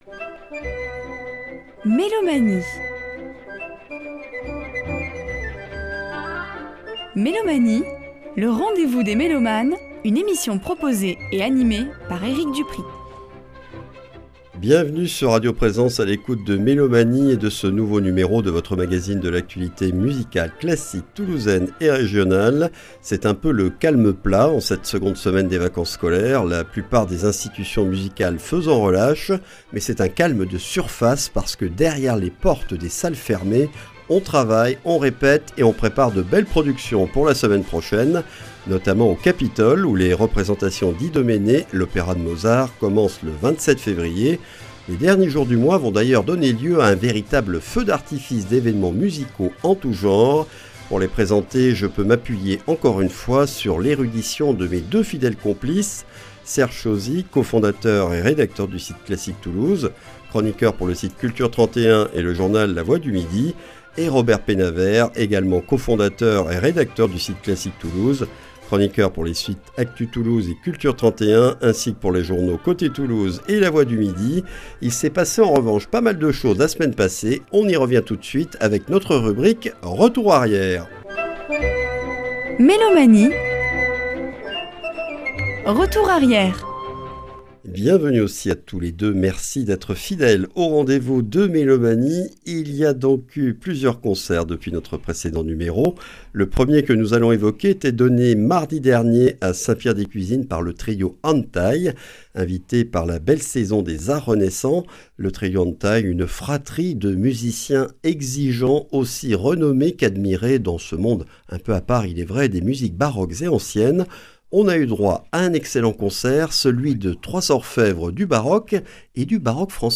Mélomanie(s) et ses chroniqueurs présentent l'actualité musicale classique de notre région.